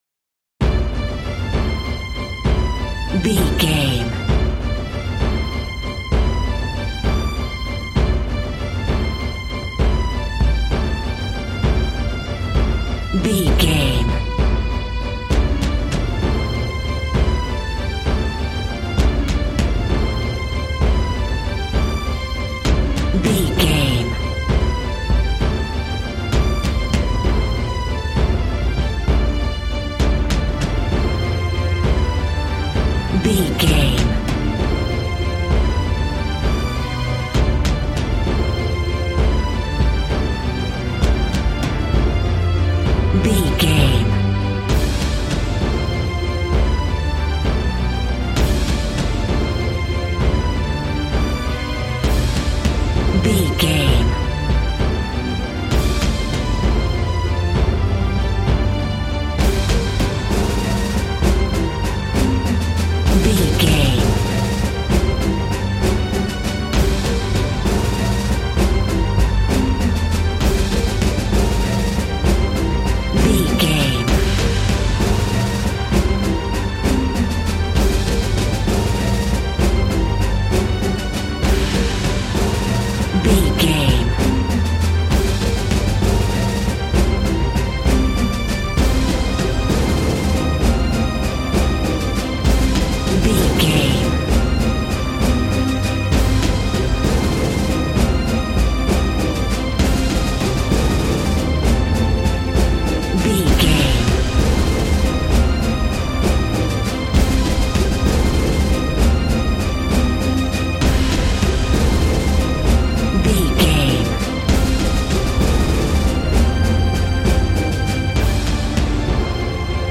Epic action music with a celtic feel.
Epic / Action
Fast paced
In-crescendo
Uplifting
Aeolian/Minor
dramatic
powerful
strings
brass
percussion
synthesiser